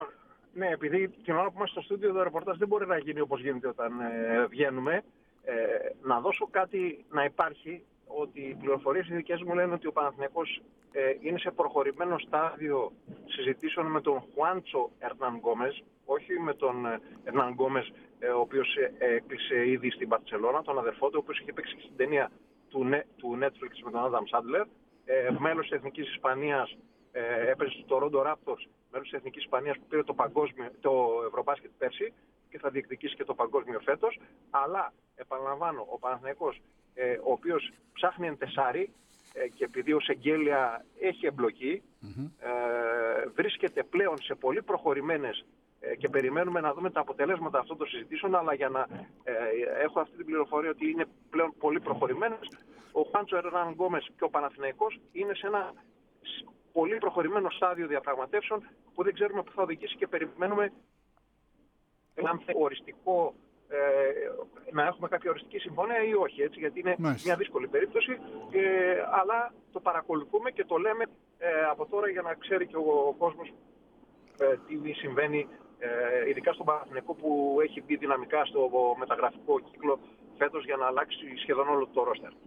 Aκούστε αναλυτικά όσα ανέφερε στην ΕΡΑ ΣΠΟΡ: